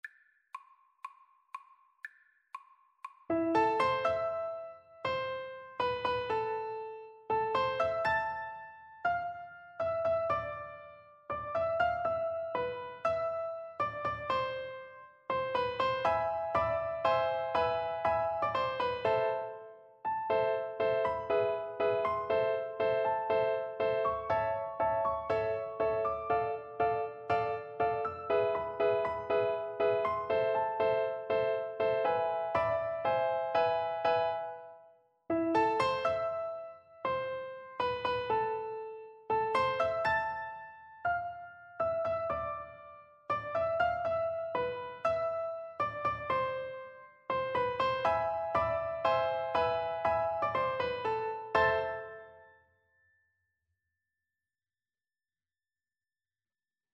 Free Sheet music for Piano Four Hands (Piano Duet)
PrimoSecondoPiano Four Hands
Allegro = 120 (View more music marked Allegro)
4/4 (View more 4/4 Music)
Piano Duet  (View more Intermediate Piano Duet Music)
World (View more World Piano Duet Music)